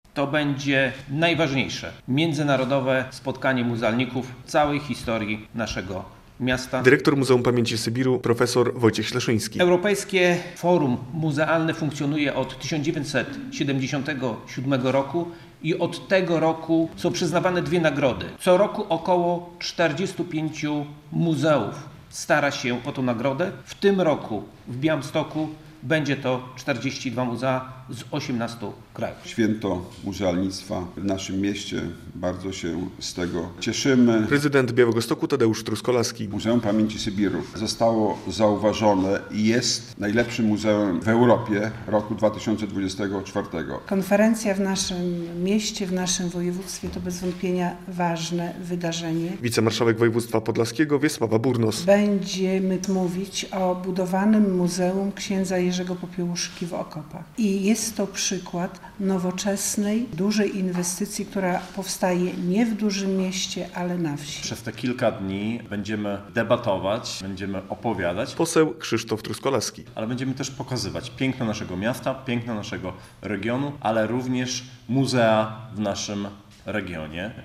Europejskie Forum Muzeów w Białymstoku - relacja